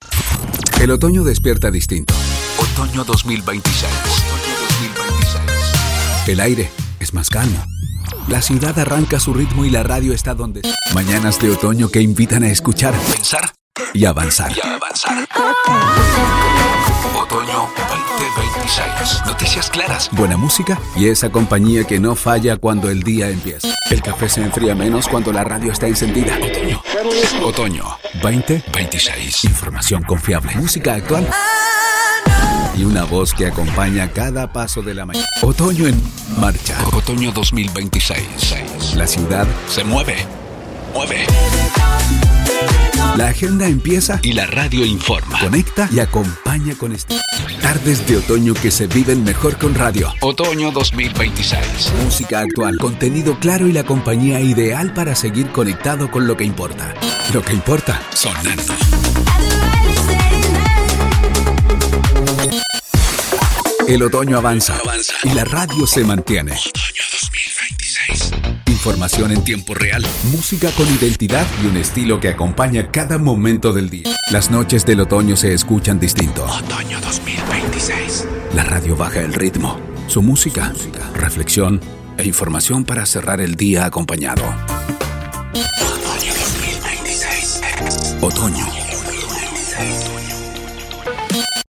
Never any Artificial Voices used, unlike other sites.
Foreign & British Male Voice Over Artists & Actors
Adult (30-50)